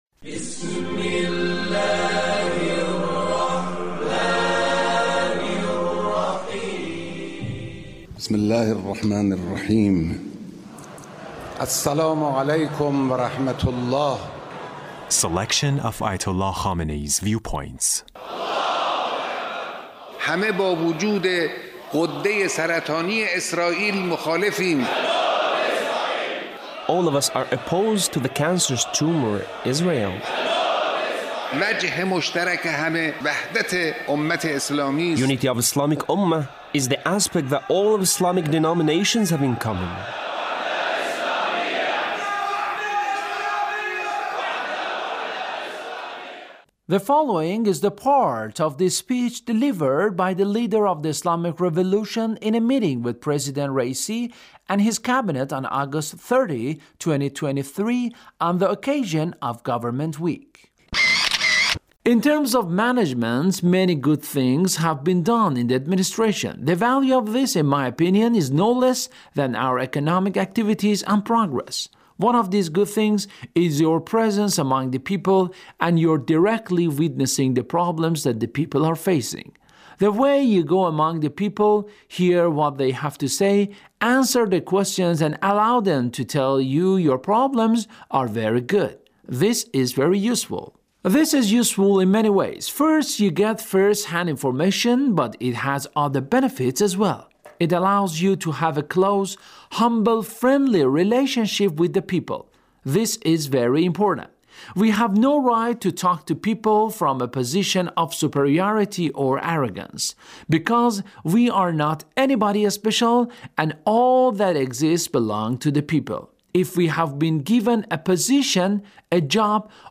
Leader's Speech with Government Officials